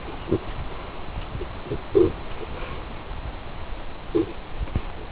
Lataa ja kuuntele sikamaista ��ntely� alla olevista linkeist�
sikarohkii.wav